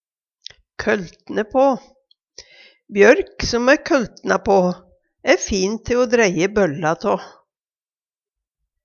køltne på - Numedalsmål (en-US)